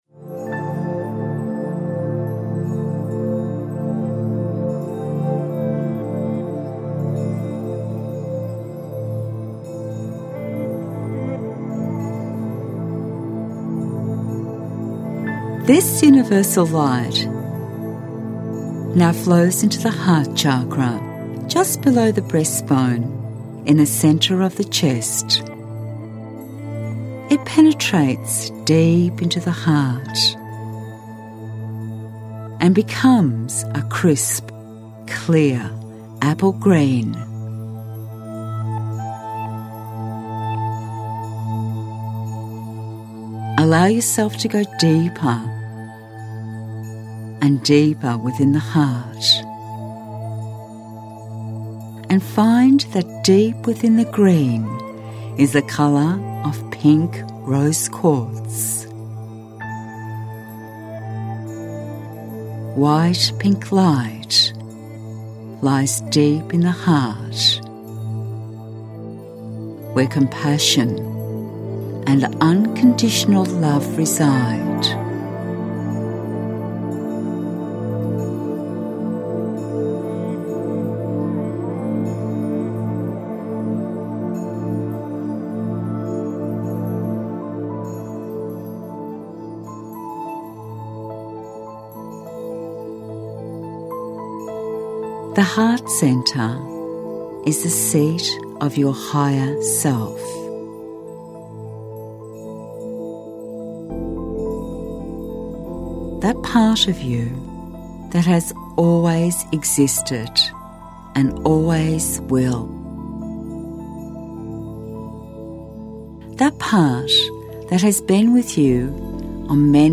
RFL Meditation Audio Sample 141 secs.mp3